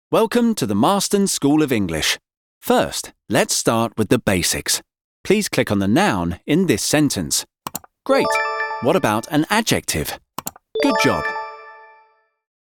Anglais (Britannique)
Commerciale, Naturelle, Polyvalente, Chaude, Corporative
E-learning
If you're looking for a smooth, versatile British male voice